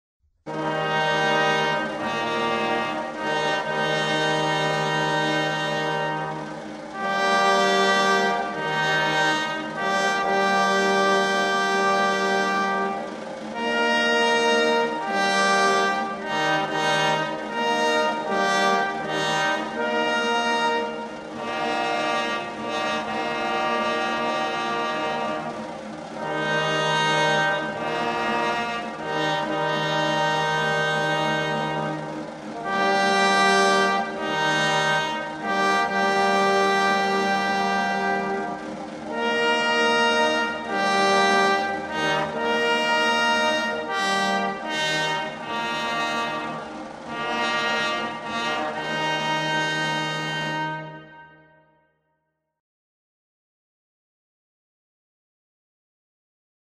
Toques e Fanfarra das Tropas Pára-quedistas